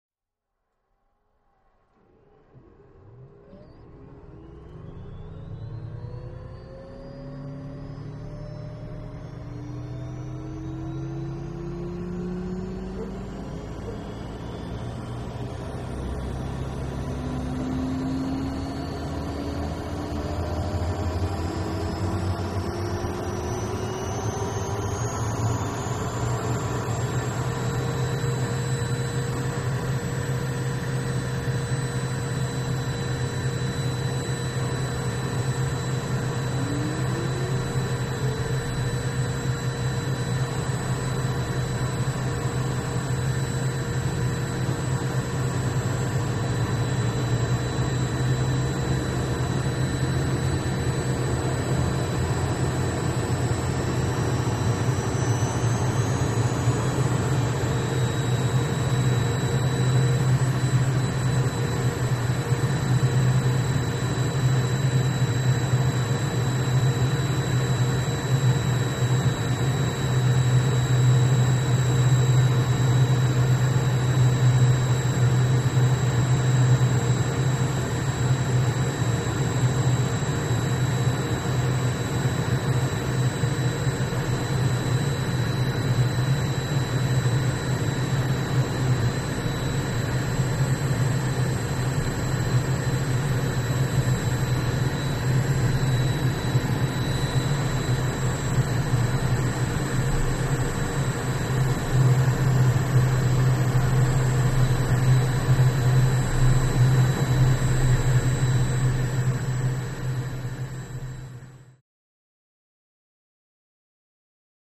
Lear Jet Engine Start And Wind Up To Full Power Idle In Several Stages